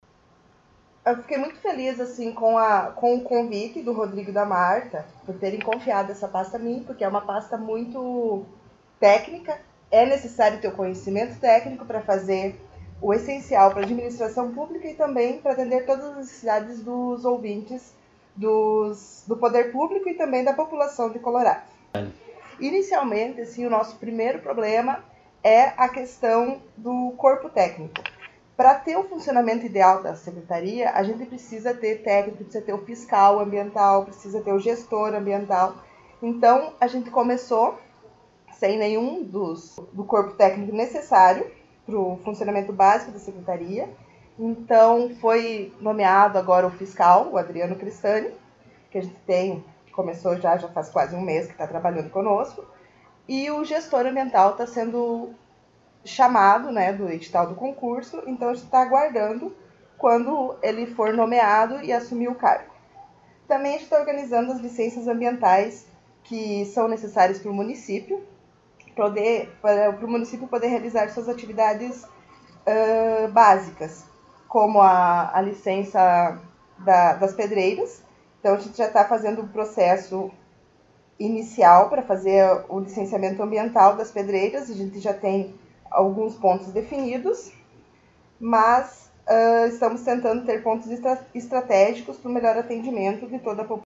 Secretária Municipal do Meio Ambiente concedeu entrevista